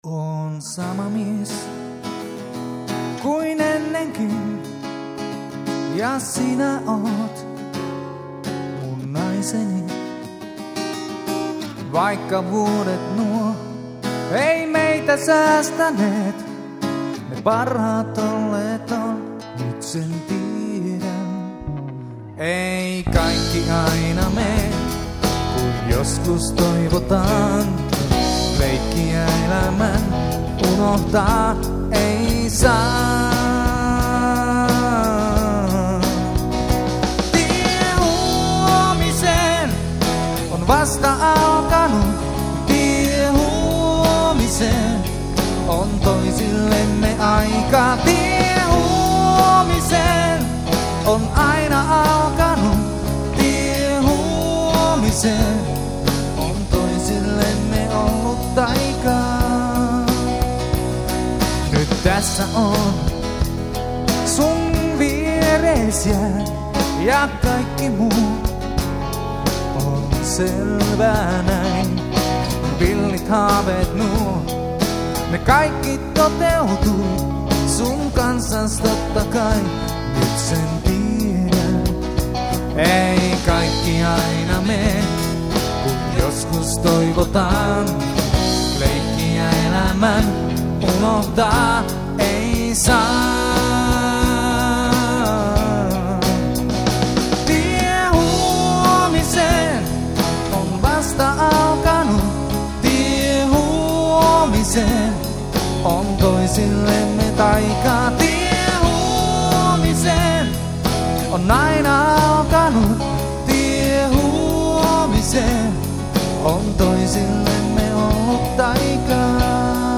Live!